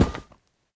sounds / block / vault / step8.ogg
step8.ogg